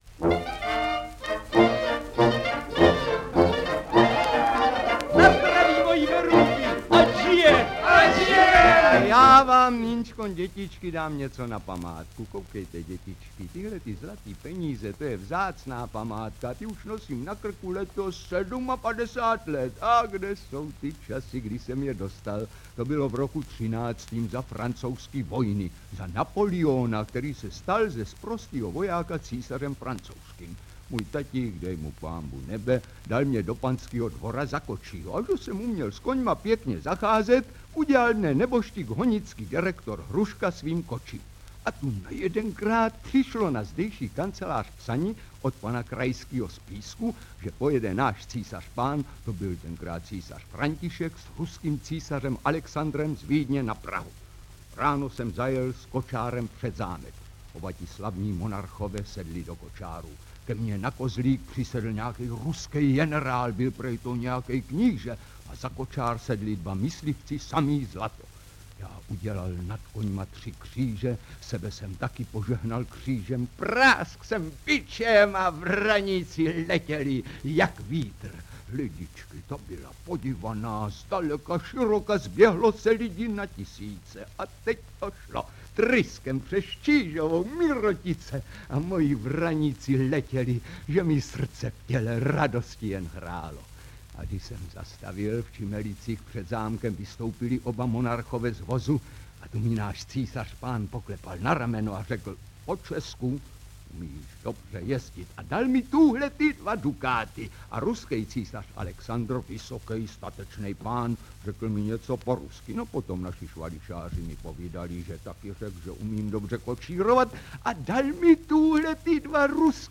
Digitální titul sestavený z historických snímků vydaných původně pod značkou Ultraphon představuje písničky a ukázky scén z inscenací Národního divadla a divadla Větrník (1941 - 1946)Byť je kvalita záznamu poplatná přepisu ze standardních desek, představují se vám hlasy a herecké či pěvecké umění legendárních umělců jako byl Bedřich Karen, Václav Vydra, Olga Scheinpflugová, František Rolland, Marie Glázrová, Zdeněk Štěpánek, Jarmila Kristenová, Otomar Korbelář a mnoho dalších.